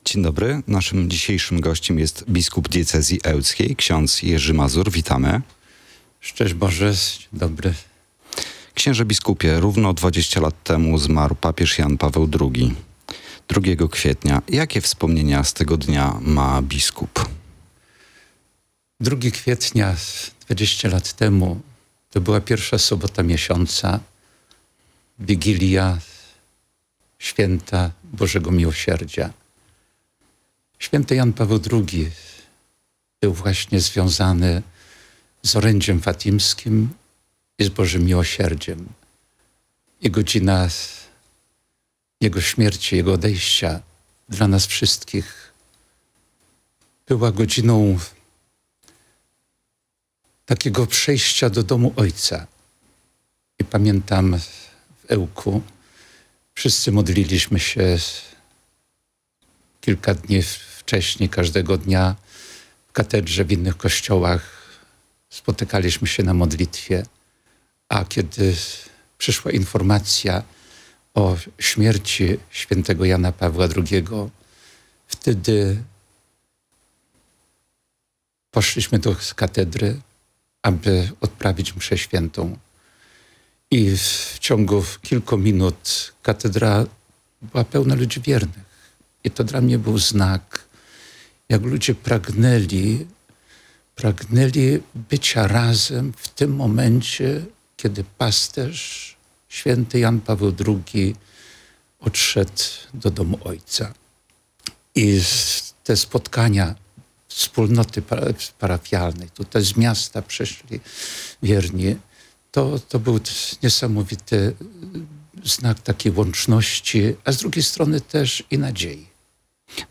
Te chwile wspominał na antenie Radia 5 biskup Diecezji Ełckiej ksiądz Jerzy Mazur.